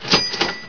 sfx_cash_register.ogg